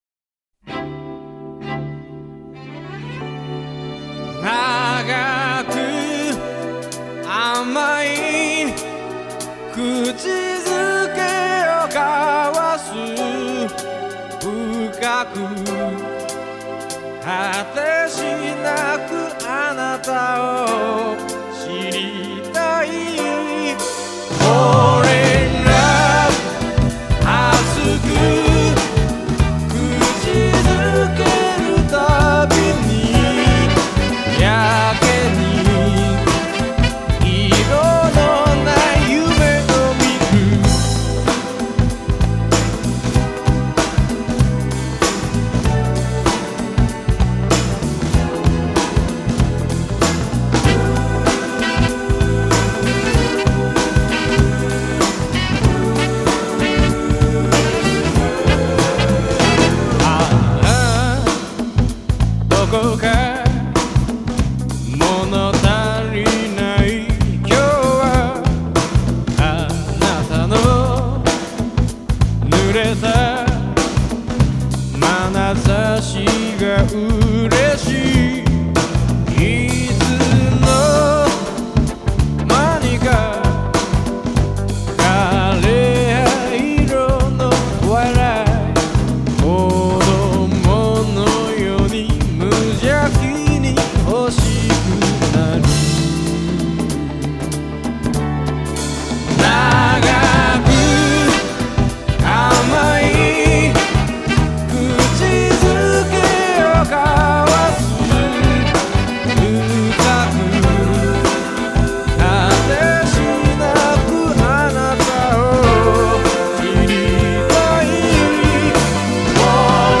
J-Pop
Strings
Percussion
상쾌한 곡이네요.